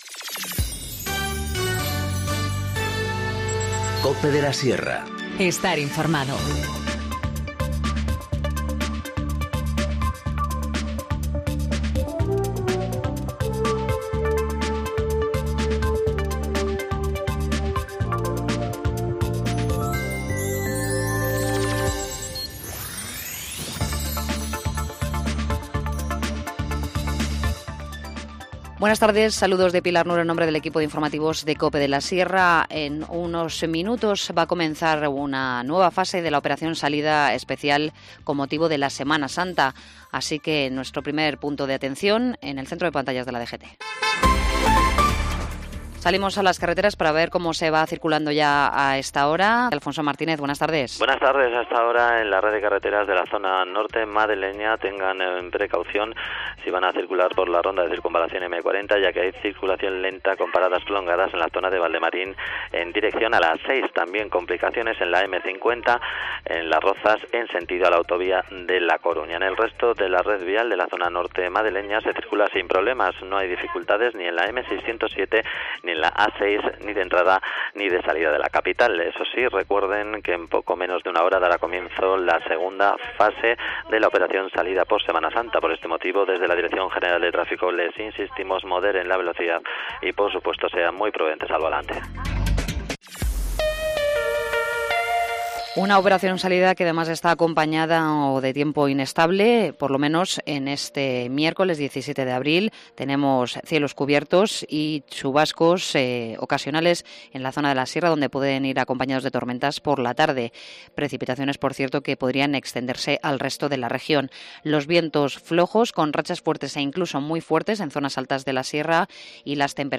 Informativo Mediodía 17 abril 14:20h